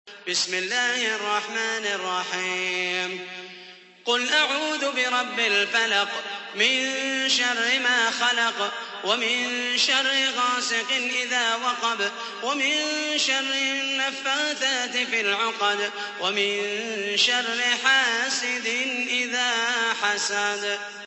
تحميل : 113. سورة الفلق / القارئ محمد المحيسني / القرآن الكريم / موقع يا حسين